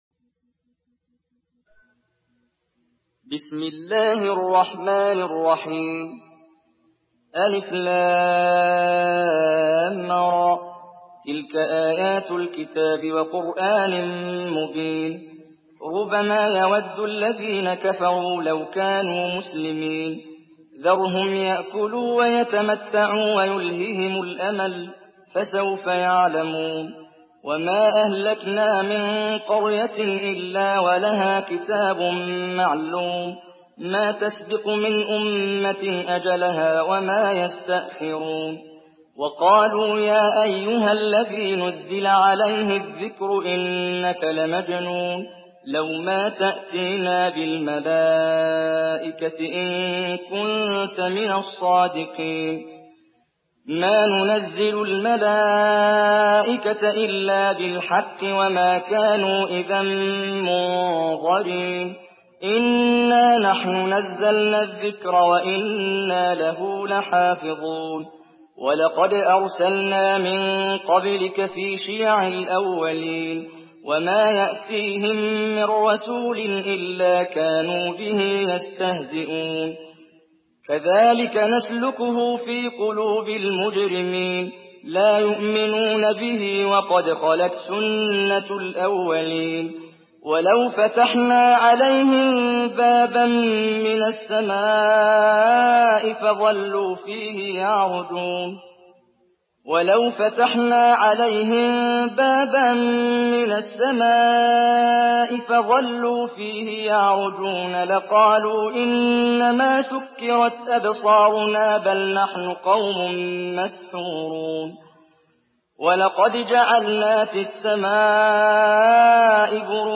Surah Hicr MP3 by Muhammad Jibreel in Hafs An Asim narration.
Murattal Hafs An Asim